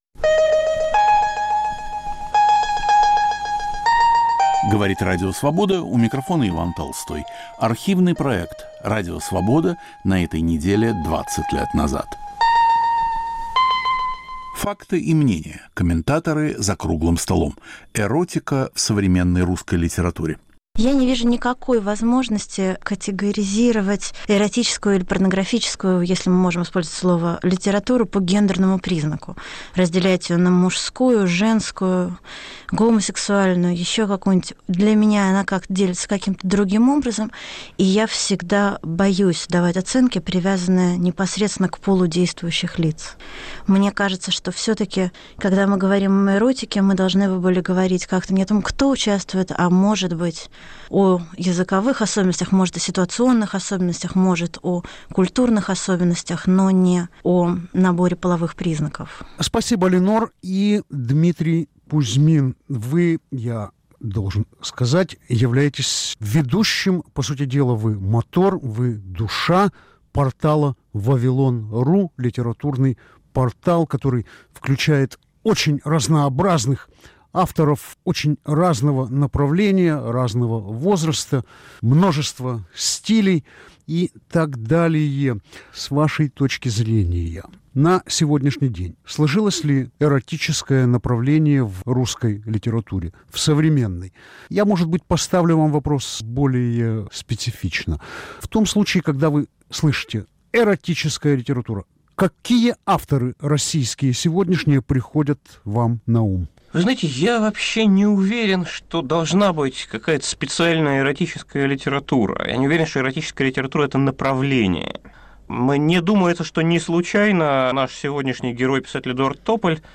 В студии Радио Свобода писатель Линор Горалик и издатель Дмитрий Кузьмин, по телефону участвует писатель Эдуард Тополь.